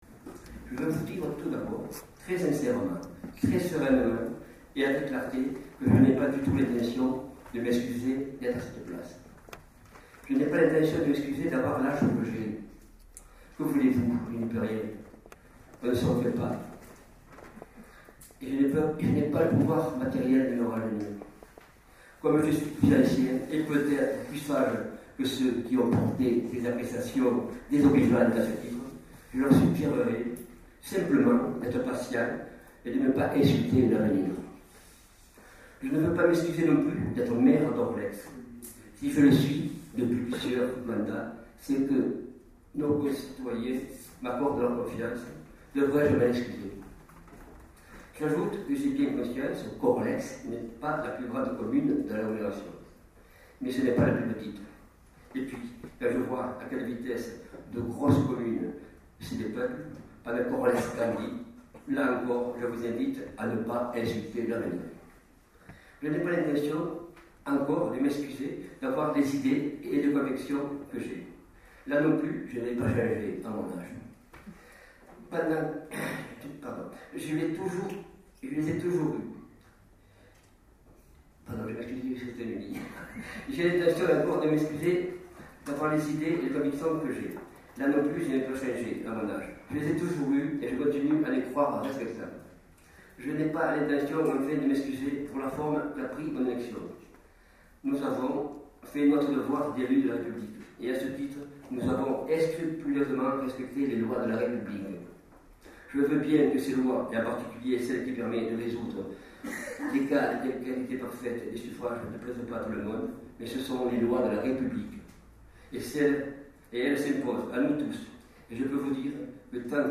Avant de demander au conseil communautaire de porter à 12 le nombre de vice-présidents (au lieu de 10), Charles Habas a pris la parole. Il est revenu sur la dernière séance, n’ayant pas l’intention de s’excuser ni pour son âge, ni de n’être que le maire d’Orleix, ni de la façon dont s’est déroulée l’élection (audio ci-dessous)